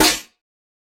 Snare 007.wav